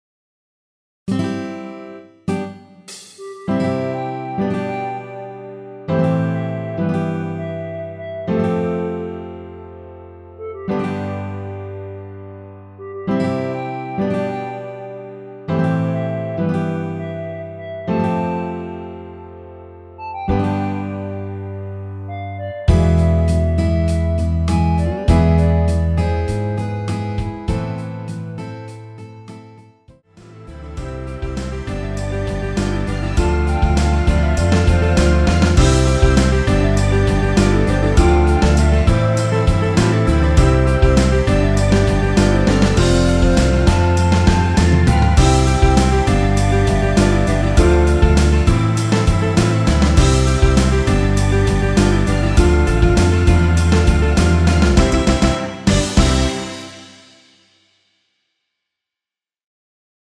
원키에서(-3)내린 멜로디 포함된 MR입니다.
전주 없이 시작 하는 곡이라 1마디 드럼(하이햇) 소리 끝나고 시작 하시면 됩니다.
앞부분30초, 뒷부분30초씩 편집해서 올려 드리고 있습니다.
중간에 음이 끈어지고 다시 나오는 이유는